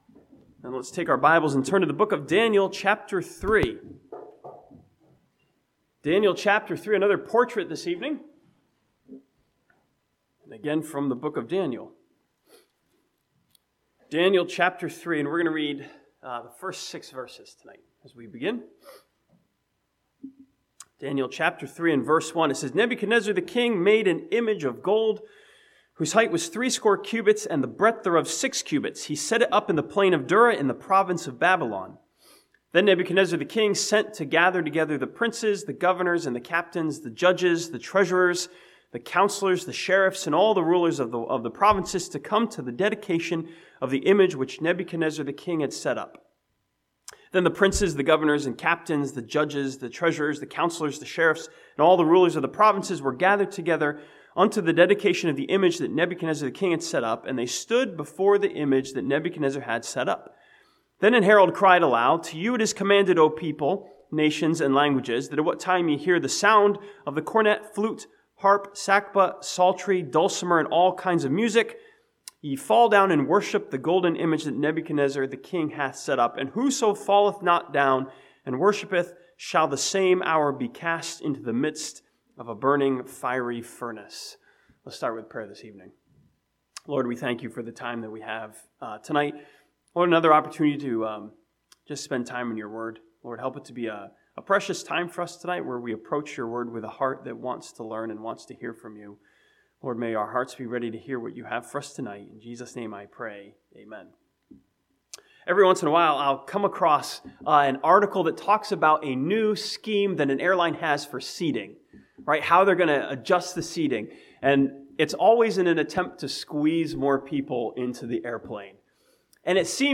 This sermon from Daniel chapter 3 studies three young Jewish boys as a portrait of standing up for what is right.